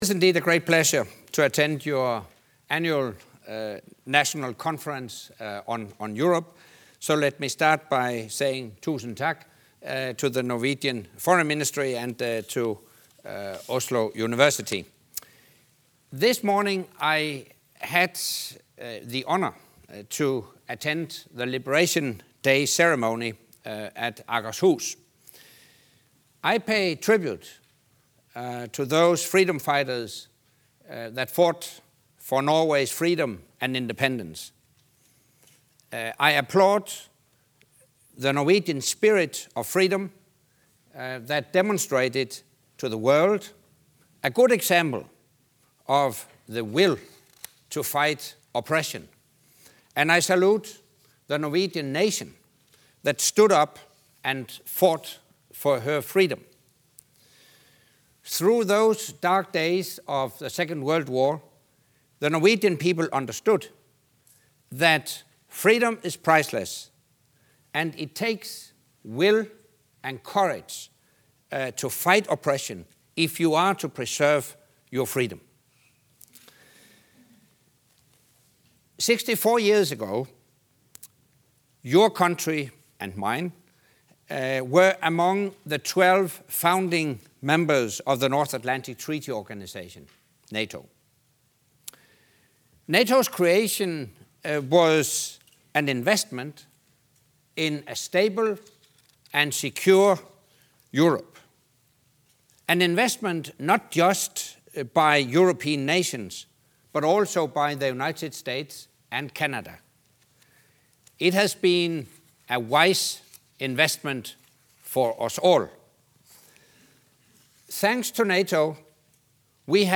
Secretary General attends Norwegian Veterans' Day commemoration